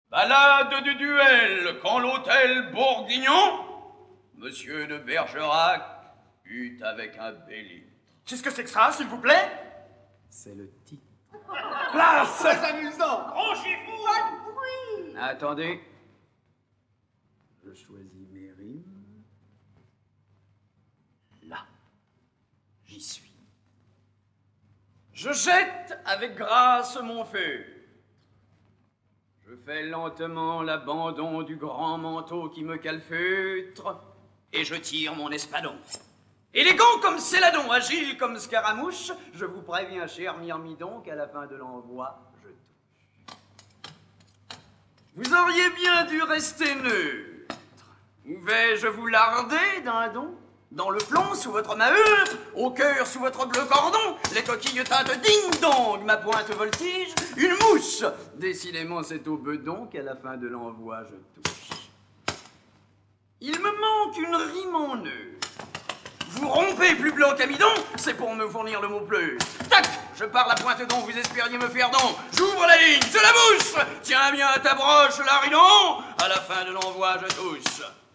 Diffusion distribution ebook et livre audio - Catalogue livres numériques
Comédie héroïque en 5 actes et en vers
Enregistrement de 1956